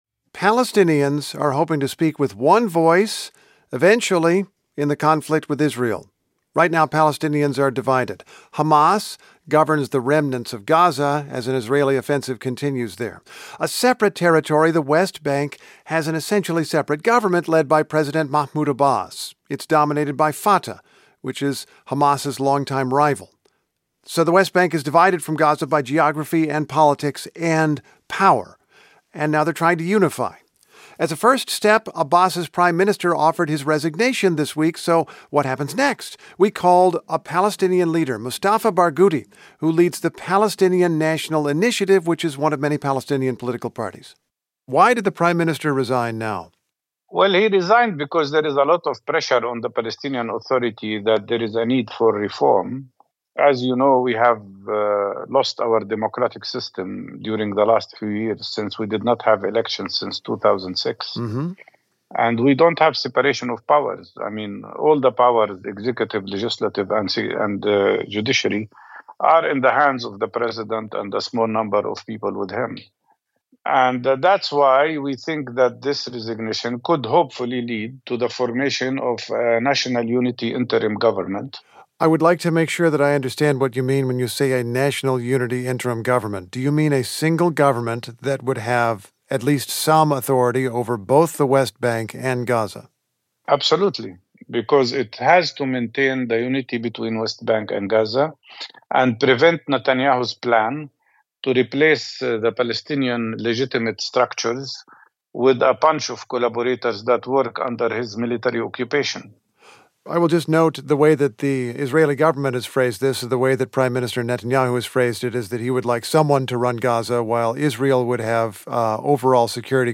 NPR's Steve Inskeep talks with Mustafa Barghouti of the Palestinian National Initiative about the resignation of the Palestinian Authority's prime minister and his government.